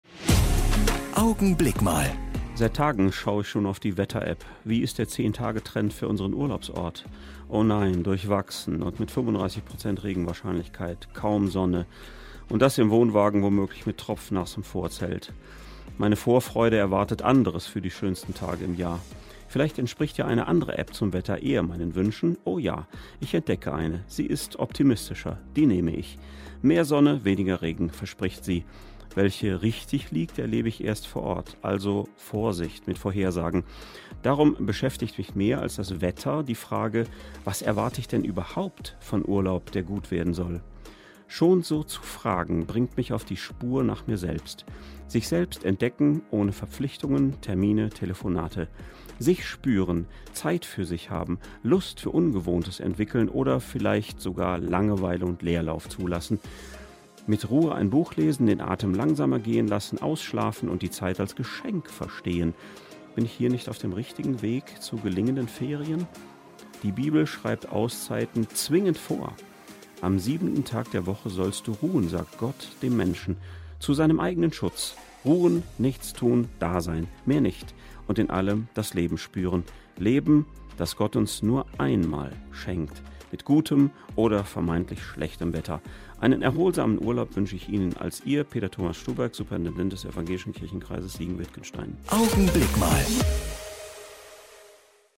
Augenblick Mal - Die Kurzandacht im Radio
Jeden Sonntag gegen halb neun bei Radio Siegen zu hören: Die Kurzandacht der Kirchen (evangelisch und katholisch) - jetzt auch hier im Studioblog zum Nachhören.